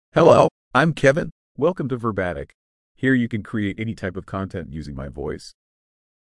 Kevin — Male English (United States) AI Voice | TTS, Voice Cloning & Video | Verbatik AI
Kevin is a male AI voice for English (United States).
Voice sample
Listen to Kevin's male English voice.
Kevin delivers clear pronunciation with authentic United States English intonation, making your content sound professionally produced.